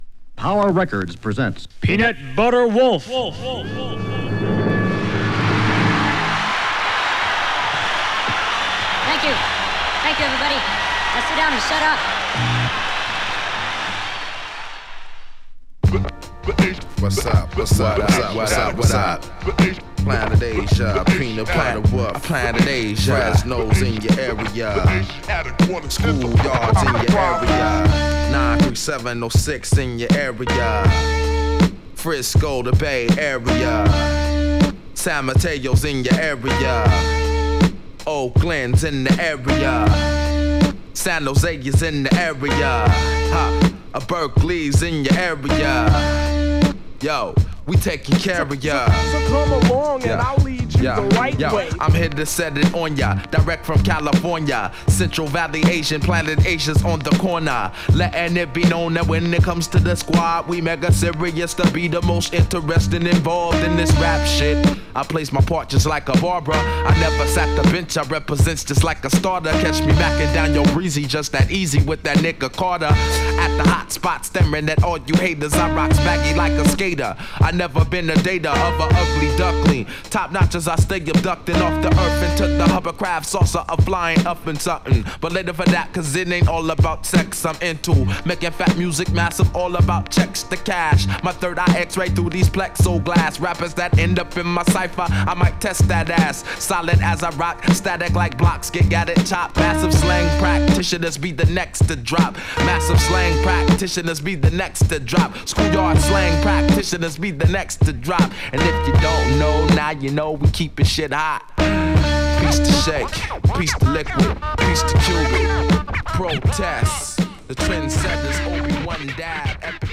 骨太なビート／厳選チョイスされたソウルやジャズなどのサンプル／テクニカルなスクラッチが、さまざまに融合。